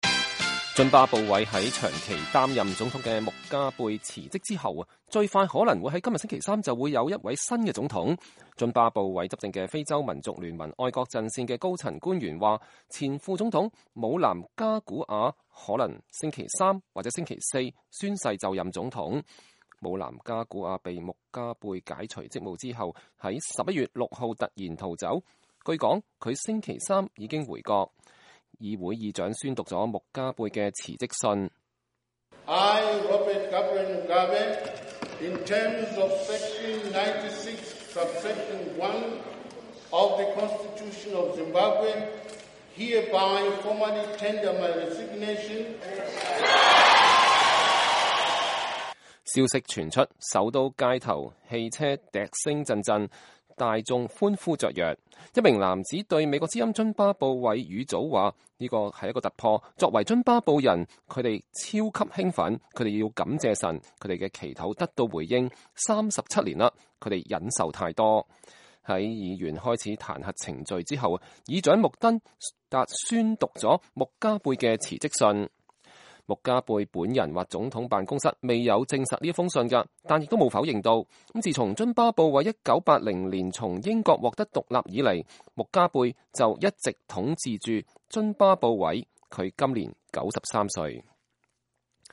津巴布韋議會議長宣讀穆加貝的辭職信